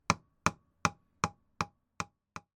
build_hammer.mp3